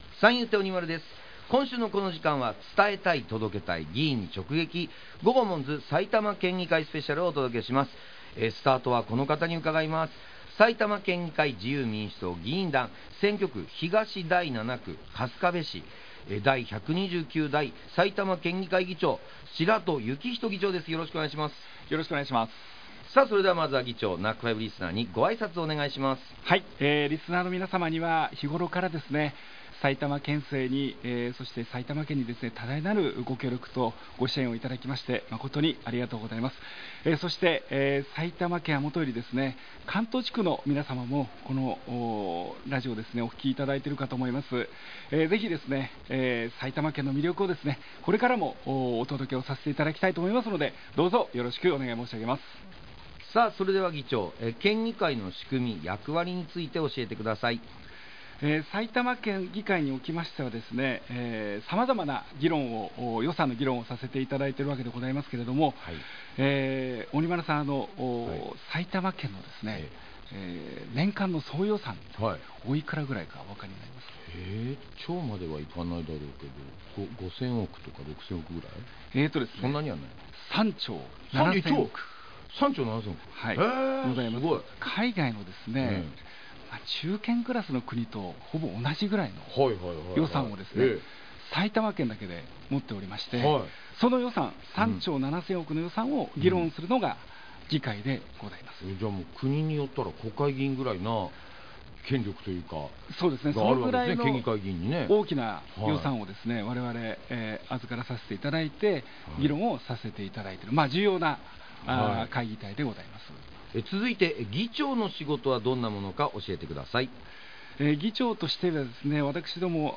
県議会議長や主要会派の議員が「GOGOMONZ」パーソナリティーで落語家の三遊亭鬼丸さんと、所属会派の紹介、力を入れている分野、議員を志したきっかけ、地元の好きなところなどについて軽快なトークを展開しました。
11月10日（月曜日）と11月11日（火曜日）にFM NACK5のスタジオにてラジオ収録が行われました。